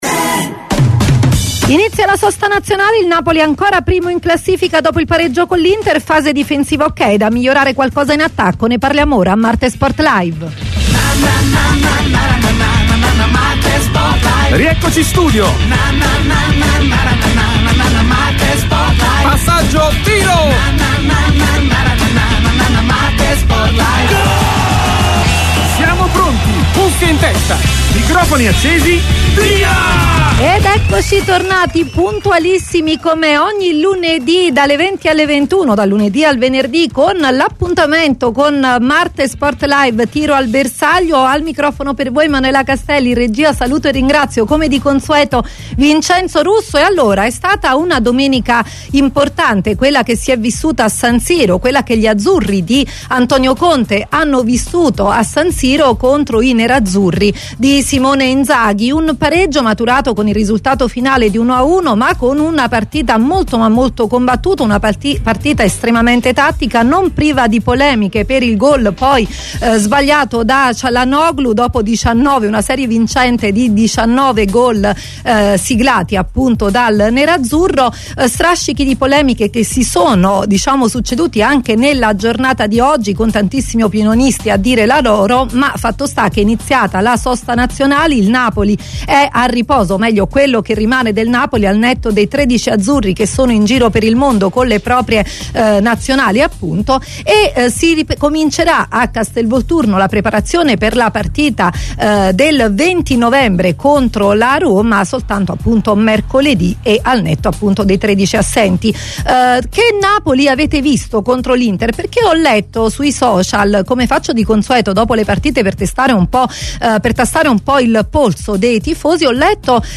MARTE SPORT LIVE è UNA TRASMISSIONE SPORTIVA, UN TALK CON OSPITI PRESTIGIOSI, OPINIONISTI COMPETENTI, EX TECNICI E GIOCATORI DI VALORE, GIORNALISTI IN CARRIERA E PROTAGONISTI DEL CALCIO ITALIANO E INTERNAZIONALE.